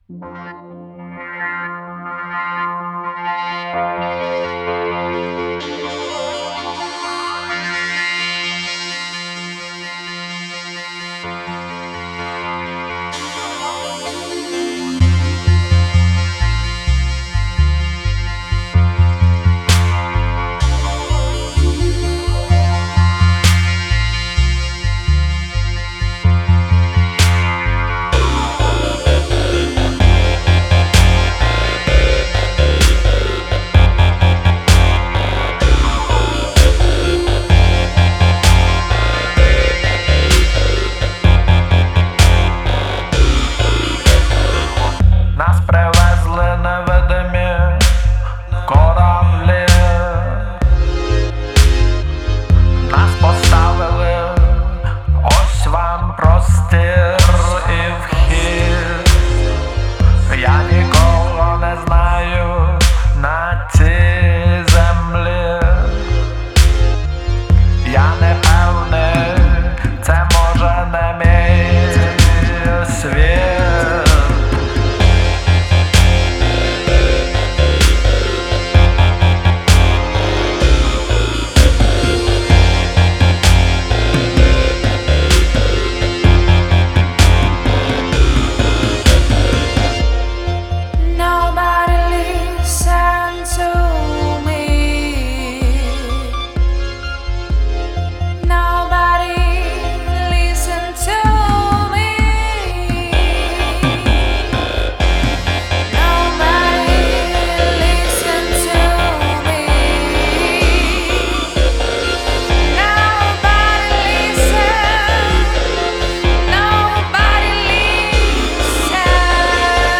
• Жанр: Electronic, Indie